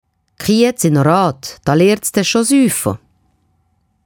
Walliser Titsch - Kiiets ino Raat …